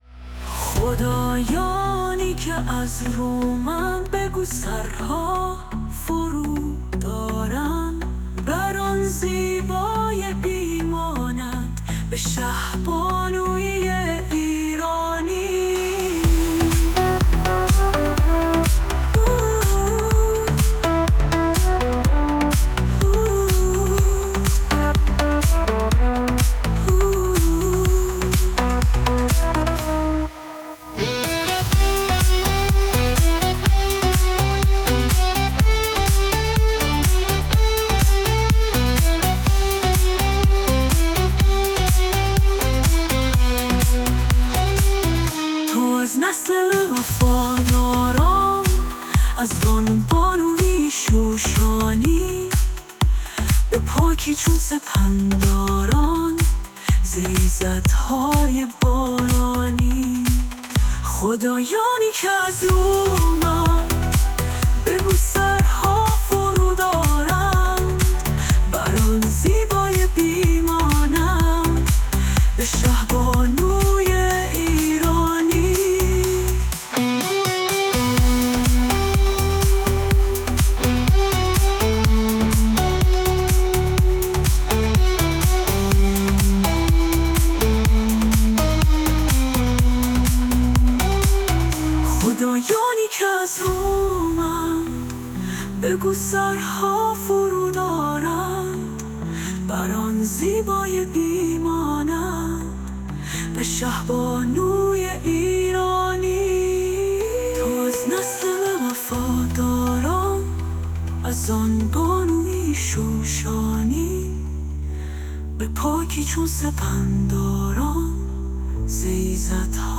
آهنگ‌های میهنی و حماسی